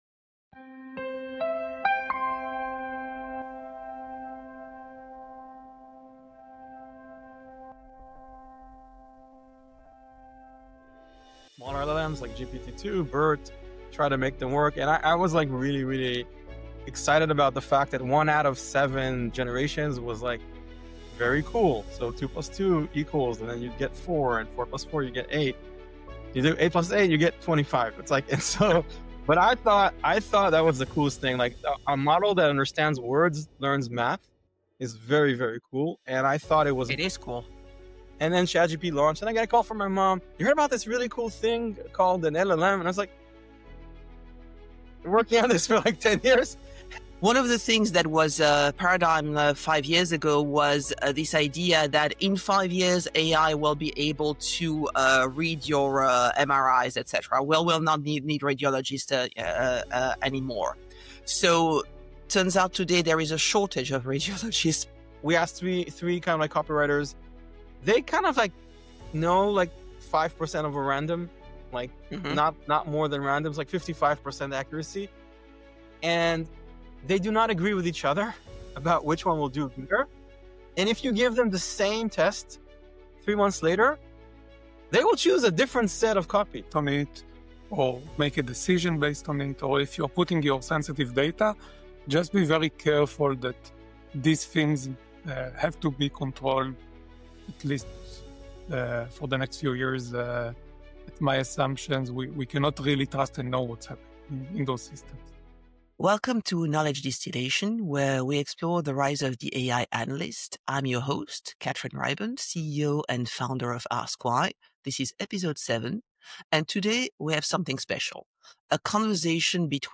Two AI engineers with combined 40 years of experience dissect their ChatGPT moment and what it means for the future of work.